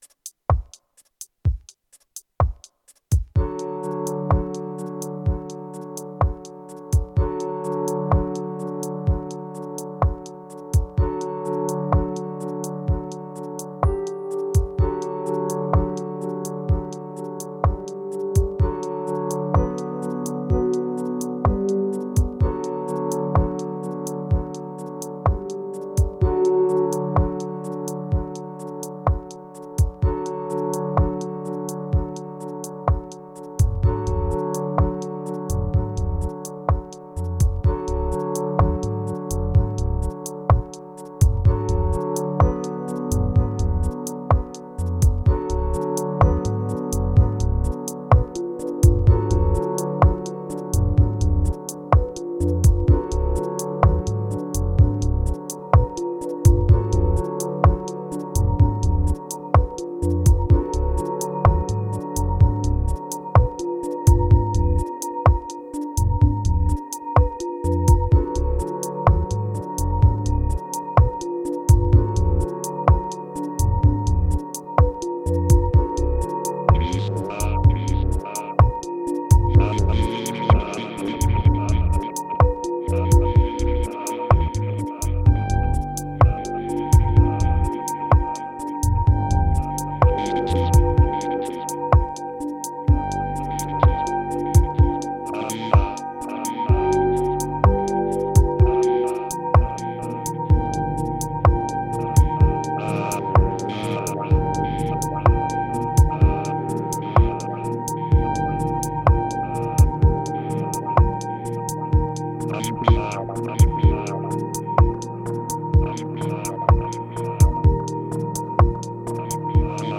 Ambient Moods Broken Deviant Healing